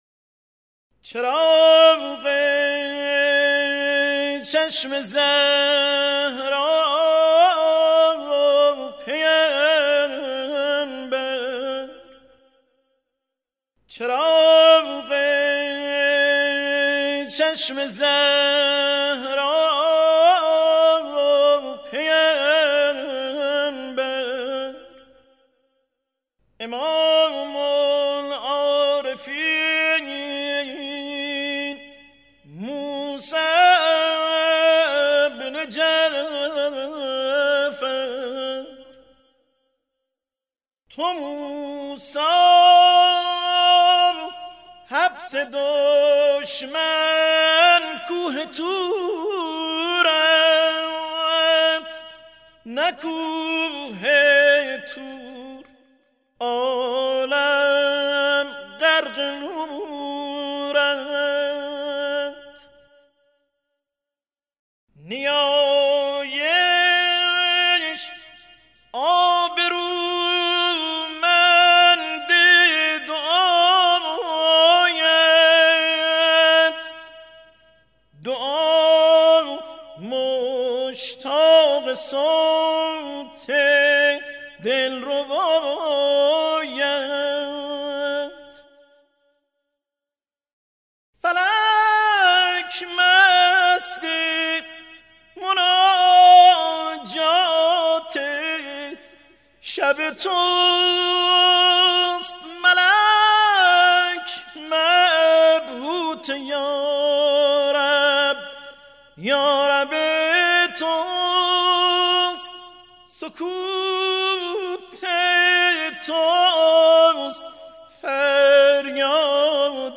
مناجات و شعرخوانی بمناسبت شهادت امام موسی کاظم علیه السلام
مناجات خوانی # حرم مطهر رضوی